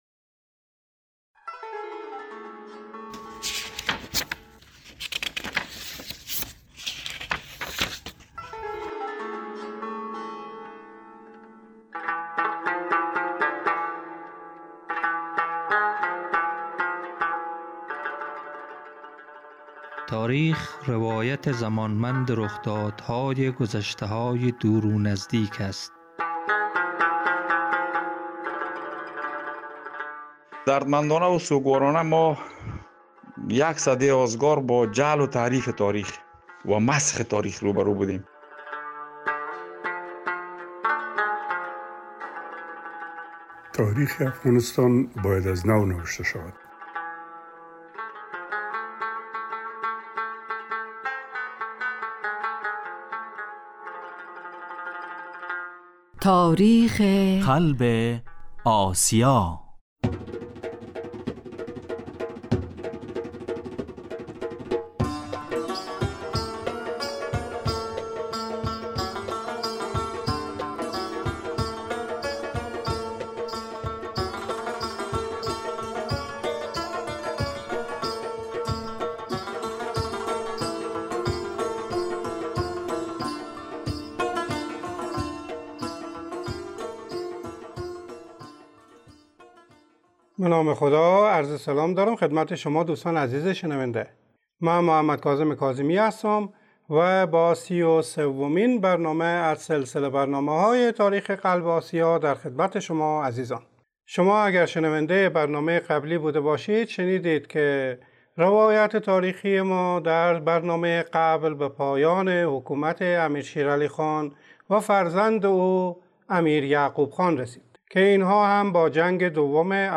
درنگی در وضعیت سیاسی، اجتماعی و فرهنگی افغانستان و نقش کمرنگ انگلیسها و امیرشیرعلی خان در آن: به روایت محمدکاظم کاظمی_ تهیه کننده